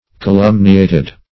Calumniated - definition of Calumniated - synonyms, pronunciation, spelling from Free Dictionary
Calumniate \Ca*lum"ni*ate\, v. i. [imp. & p. p. Calumniated;
calumniated.mp3